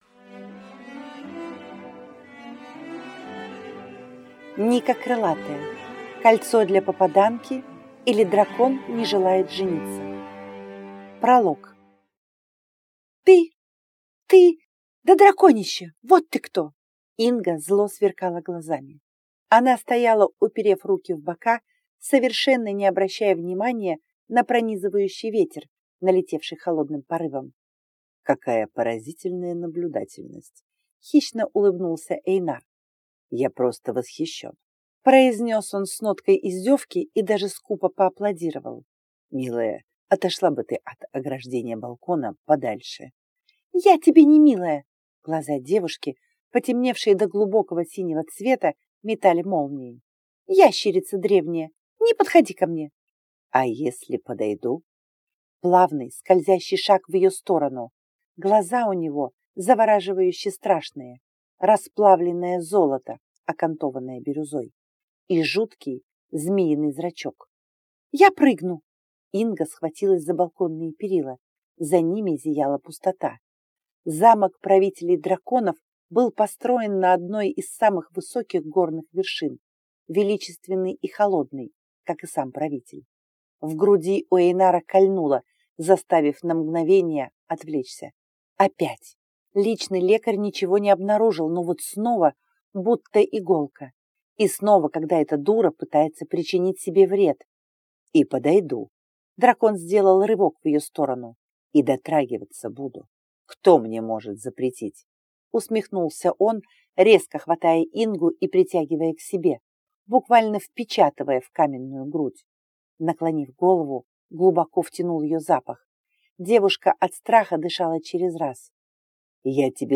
Аудиокнига Кольцо для попаданки, или Дракон (не) желает жениться | Библиотека аудиокниг